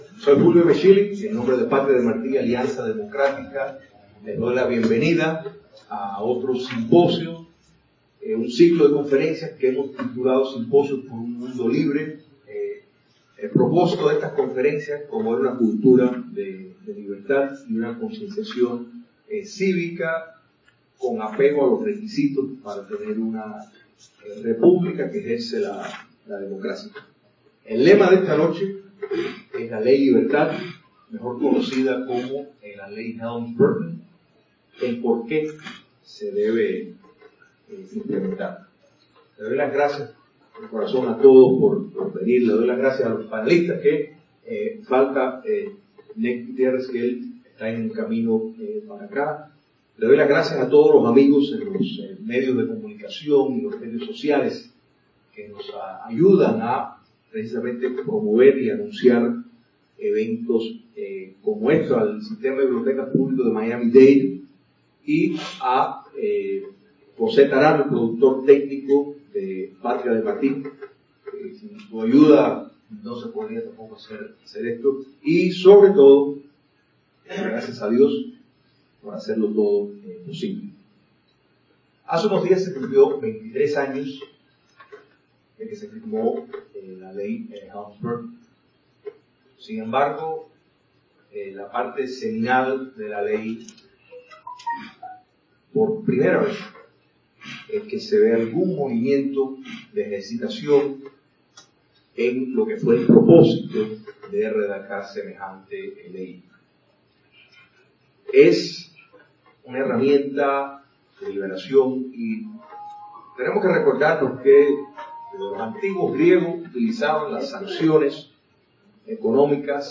Audios de programas televisivos, radiales y conferencias